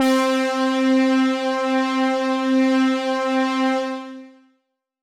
Synth Pad C4.wav